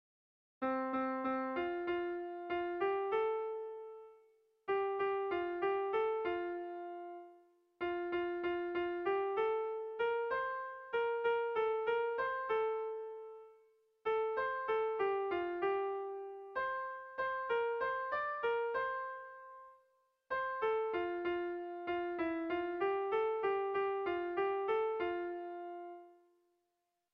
Irrizkoa
Leitza < Larraun Leitzaldea < Nafarroa < Basque Country
Zortziko txikia (hg) / Lau puntuko txikia (ip)